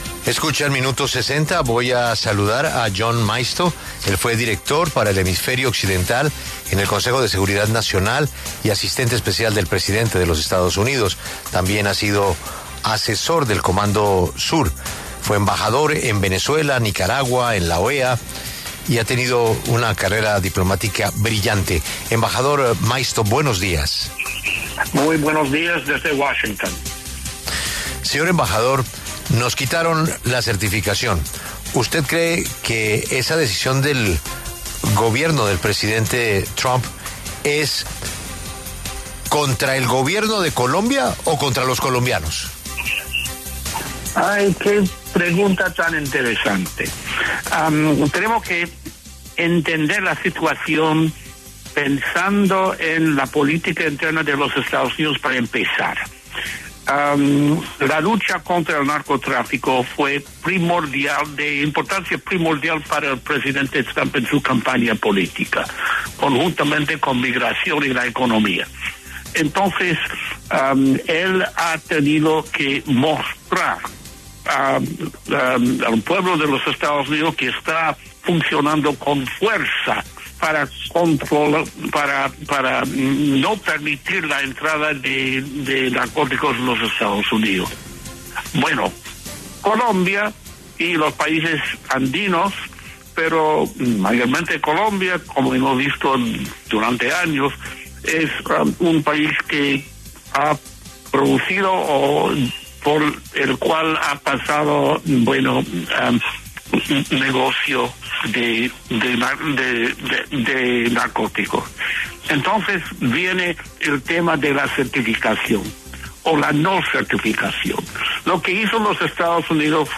John Maisto, exasesor de Asuntos Exteriores en el Comando Sur de EE.UU. y exembajador, conversó con La W sobre la descertificación de Colombia en la lucha contra las drogas.